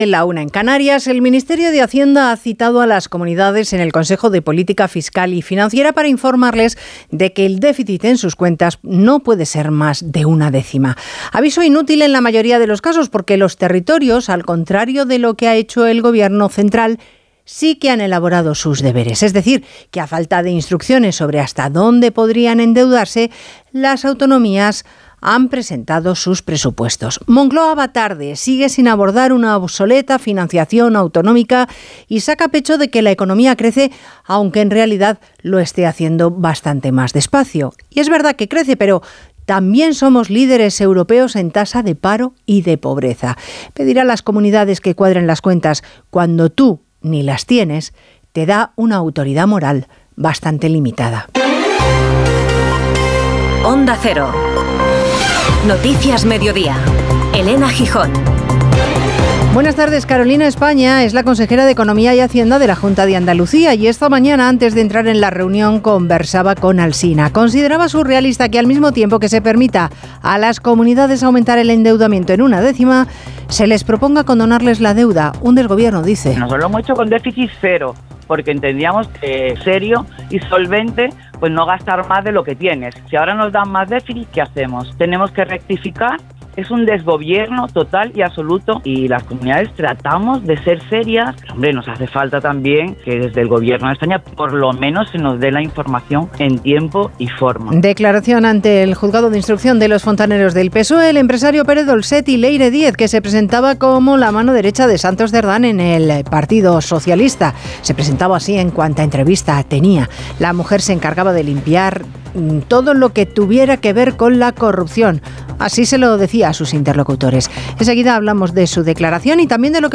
Descárgate el programa completo, entrevistas, crónicas o reportajes